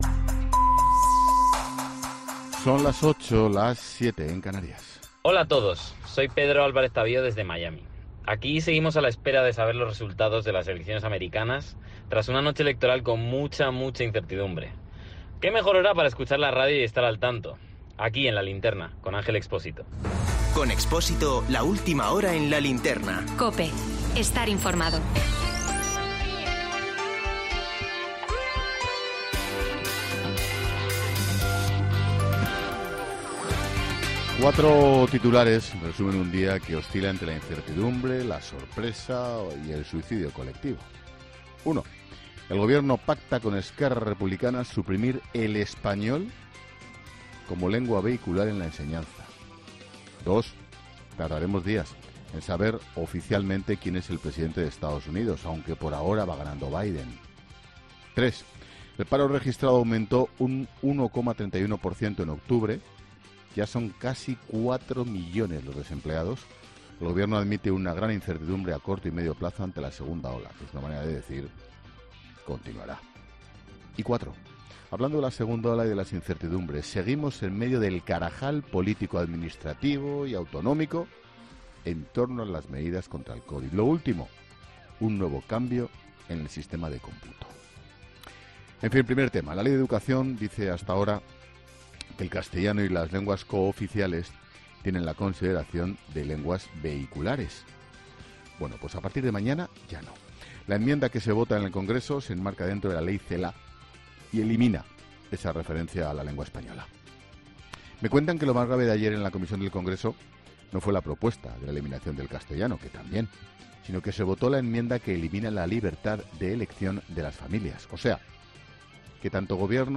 Monólogo de Expósito
El director de 'La Linterna', Ángel Expósito, analiza las elecciones estadounidenses, los datos del paro, la evolución epidemiológica y la 'ley Celáa'